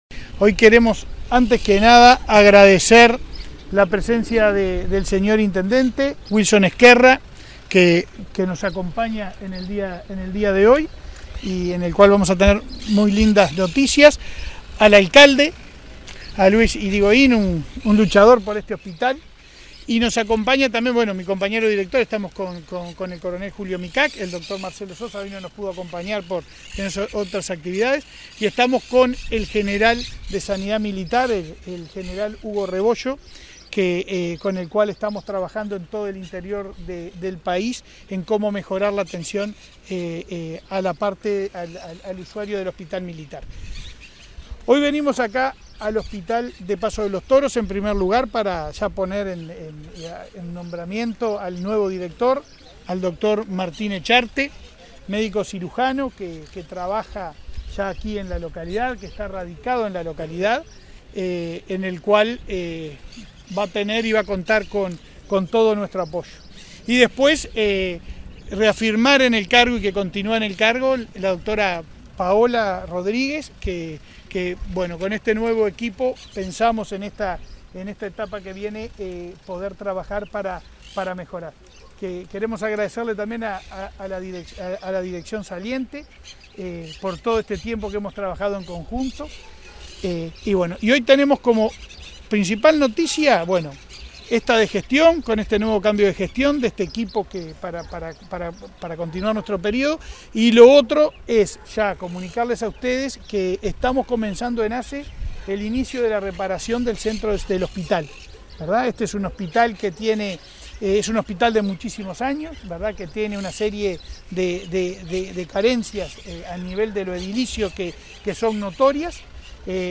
Declaraciones del presidente de ASSE, Leonardo Cipriani
Declaraciones del presidente de ASSE, Leonardo Cipriani 17/10/2023 Compartir Facebook X Copiar enlace WhatsApp LinkedIn Tras la entrega de una ambulancia 0 km y el anunció de remodelación edilicia del Hospital de Paso de los Toros, este 17 de octubre, el presidente de la Administración de Servicios de Salud del Estado (ASSE), Leonardo Cipriani, realizó declaraciones a la prensa.